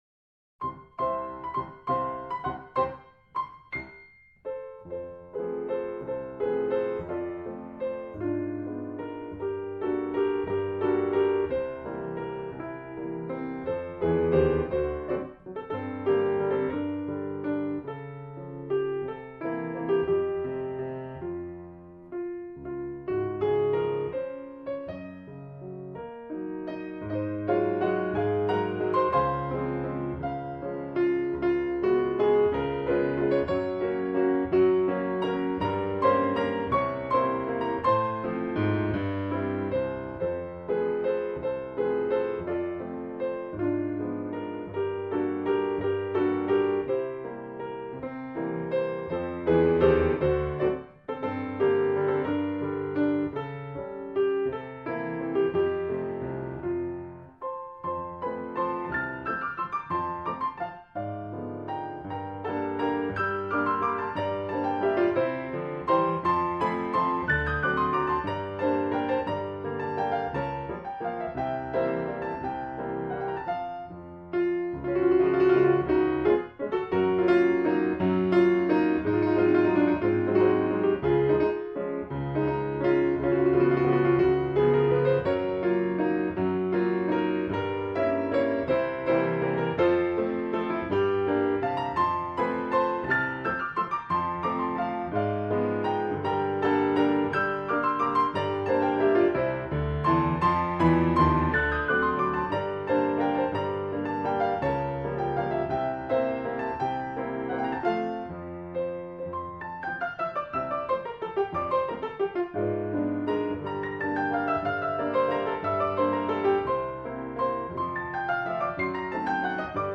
เพลงพระราชนิพนธ์, เปียโน